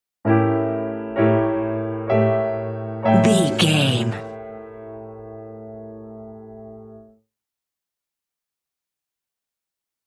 In-crescendo
Thriller
Diminished
scary
ominous
dark
suspense
haunting
eerie
piano
short stinger
short music instrumental
horror scene change music